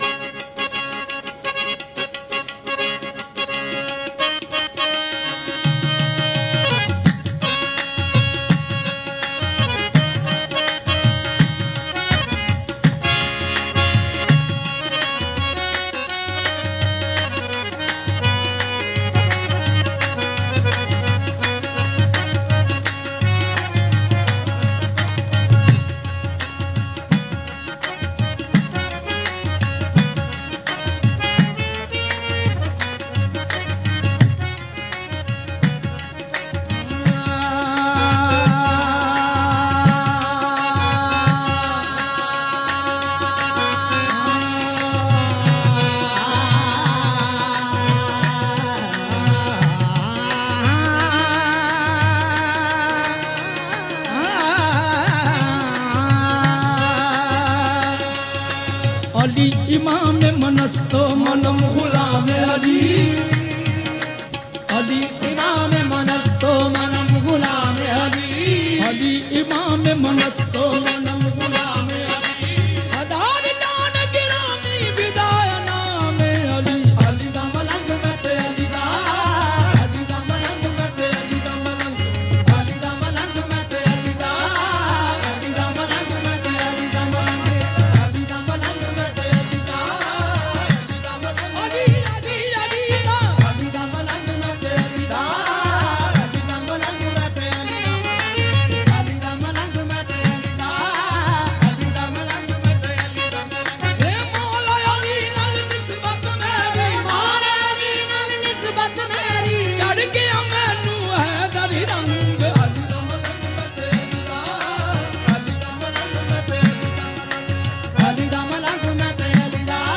Milad un Nabi – Naat and Naatiya Kalam